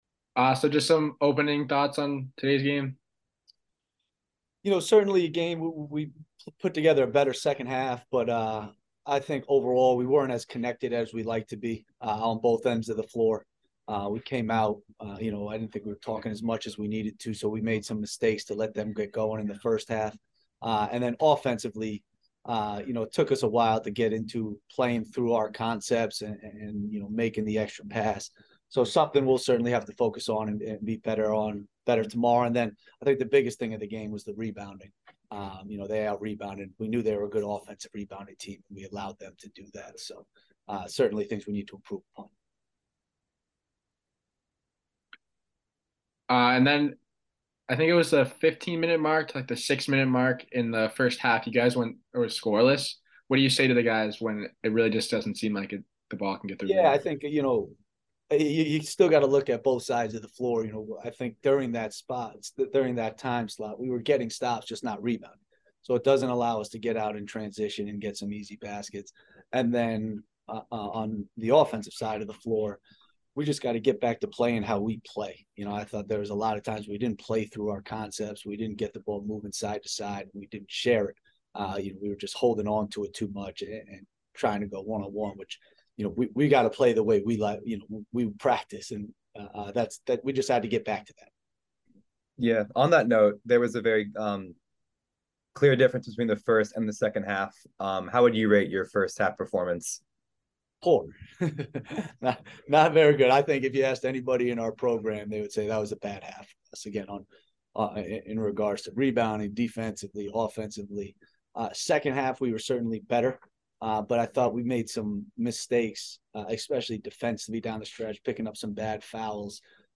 SEMO Postgame Interview